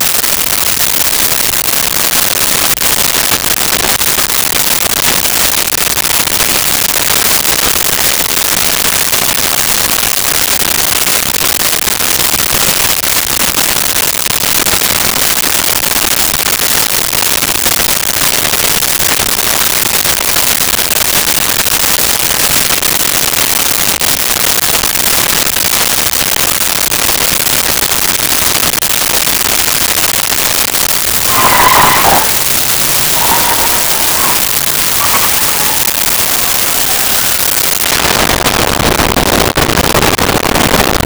Car Start Idle Fishtail Out
Car Start Idle Fishtail Out.wav